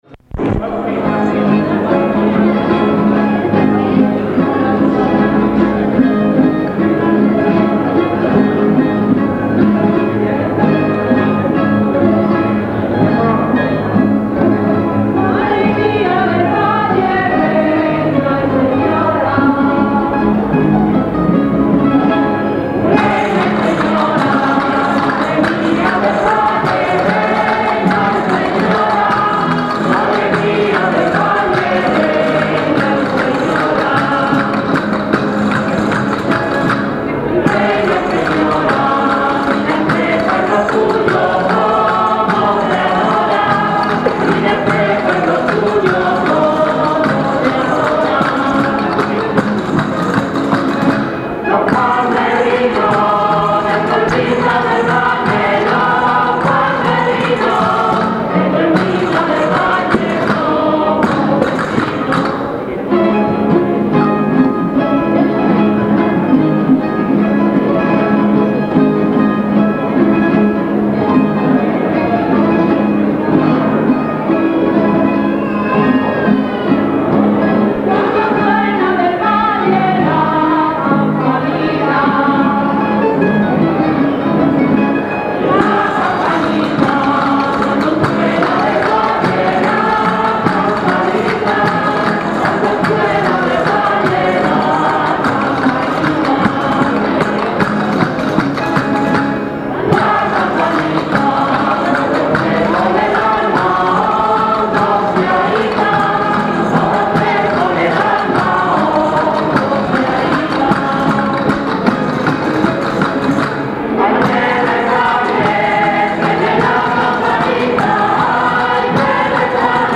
Sevillanas a Ntra. Sra. del Valle
sevillanas.mp3